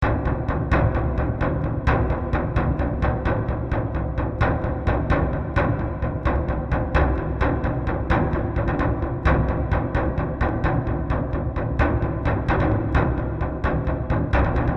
家庭的声音 " 恐怖的音乐
描述：可怕的气氛声
标签： 大气 很吓人 声音
声道立体声